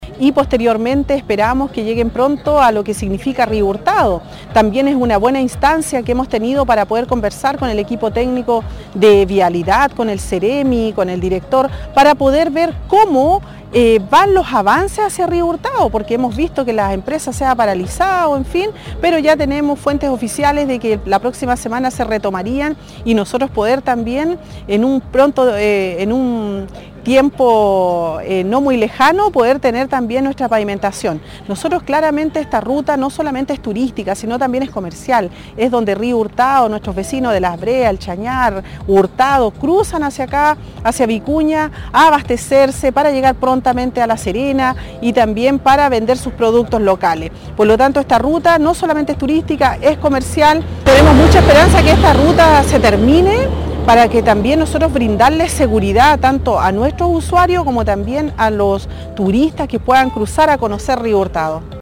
La alcaldesa de Río Hurtado Carmen Juana Olivares destacó la primera etapa de la pavimentación, esperando que prontamente se inicien los trabajos en Río Hurtado.